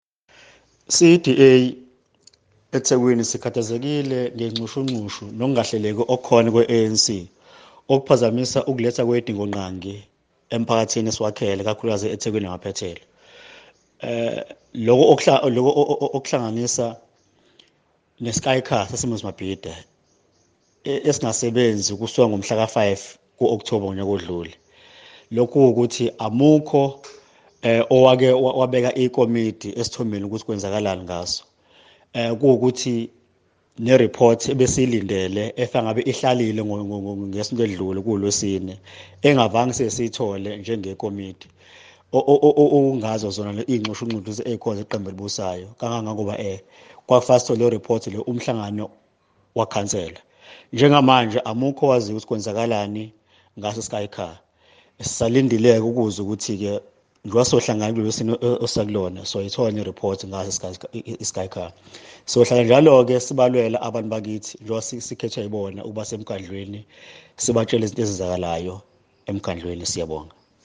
Issued by Cllr Vincent Mkhize – DA eThekwini Councillor
Note to Editors: Find attached soundbites in English and